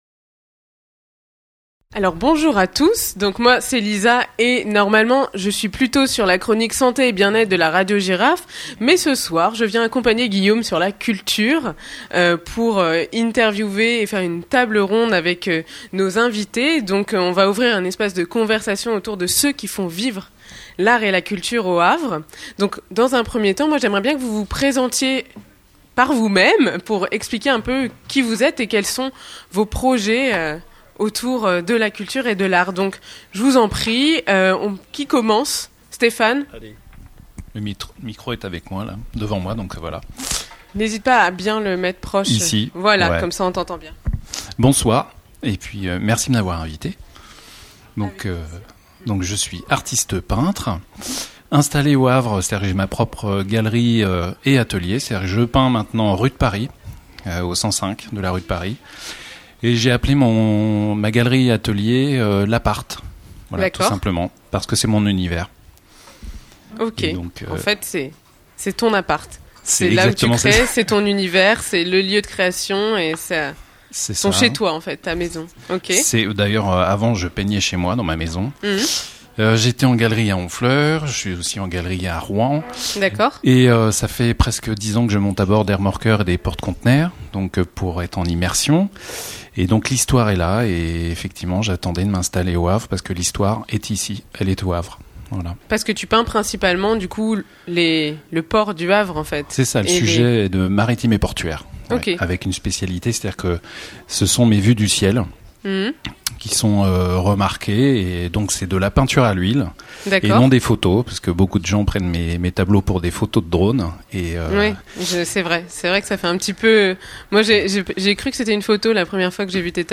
On a discuté de ce qui rend la ville si spéciale pour les artistes, entre la lumière de l'estuaire, le béton qu'on finit par adorer et ce sentiment de liberté qu'on trouve "au bout du monde". Pas de chichis, juste un échange sincère sur la peinture, la musique et l'envie de créer ici.